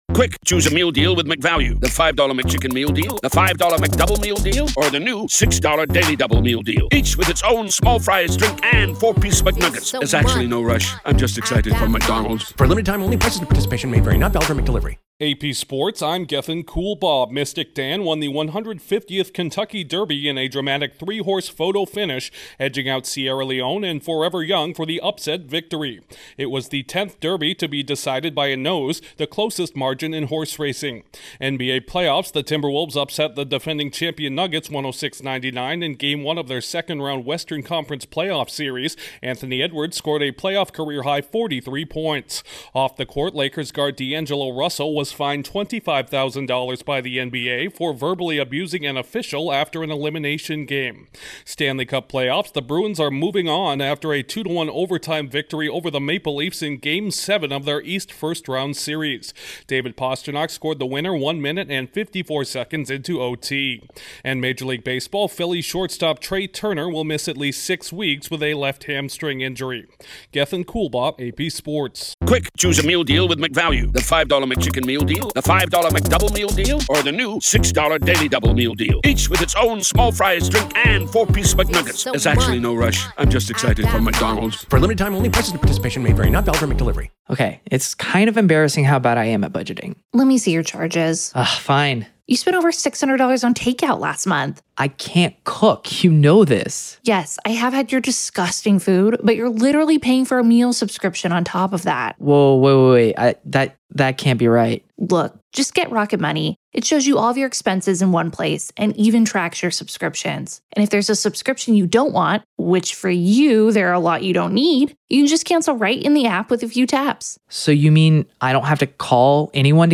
A photo finish at the Kentucky Derby, an upset victory to open Round Two of the NBA Playoffs, a thrilling OT victory in Game 7 of the Stanley Cup Playoffs and more. Correspondent